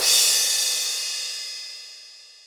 Tm8_HatxPerc56.wav